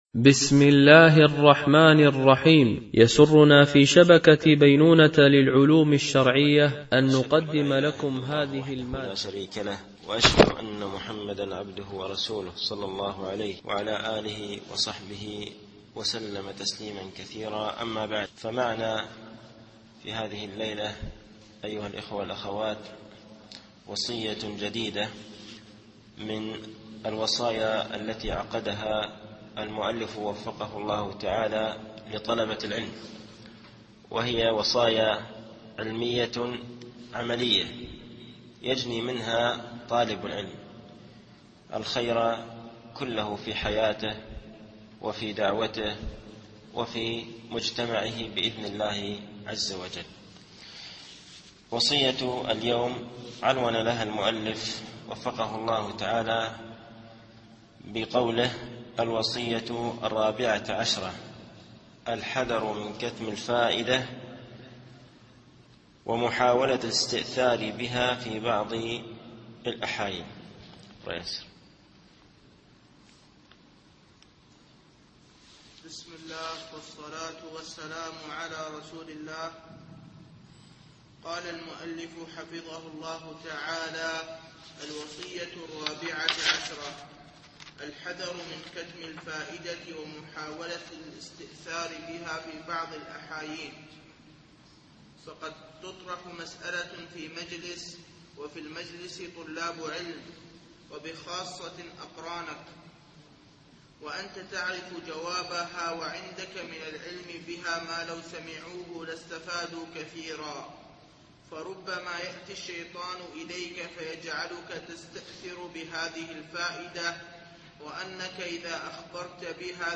التعليق على كتاب معالم في طريق طلب العلم (وصية14فقه نشر الفوائد وكتمانها) - الدرس التاسع و الأربعون